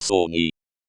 soŋ.i/ “bunch [of flowers, grapes, …]”.